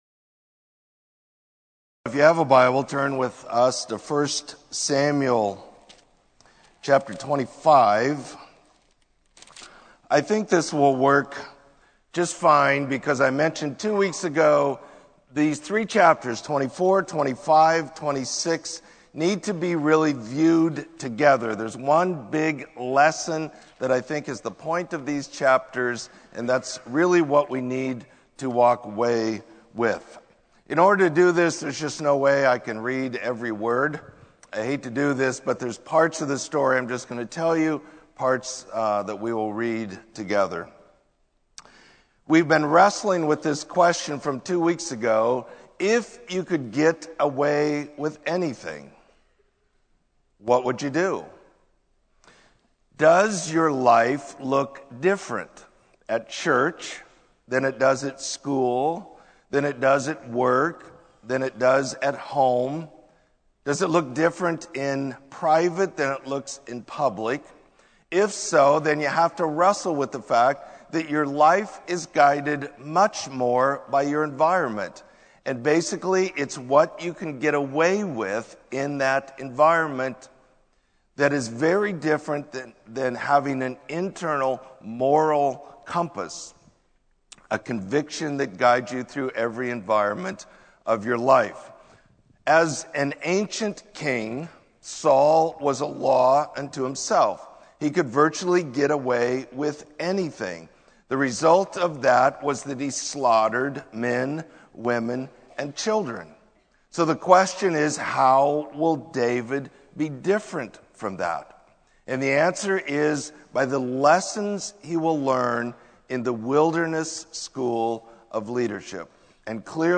Sermon: The Growth of a Leader